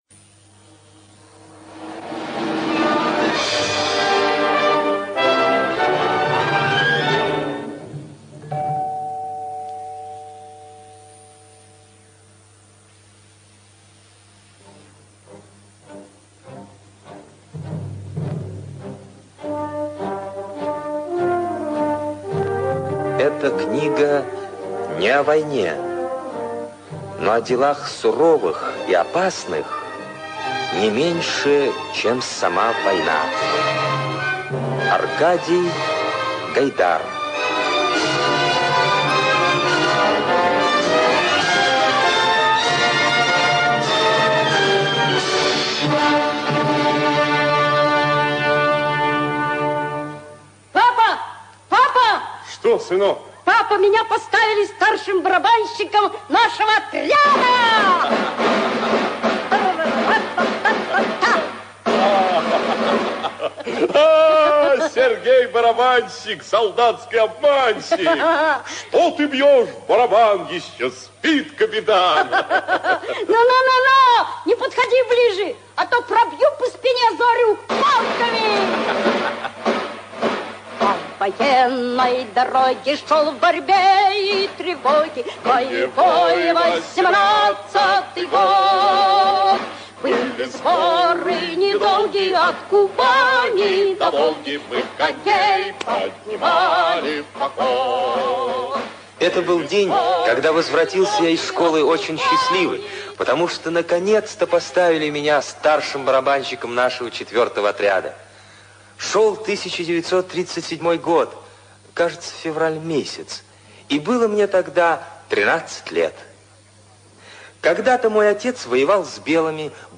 Судьба барабанщика - аудио повесть Гайдара - слушать онлайн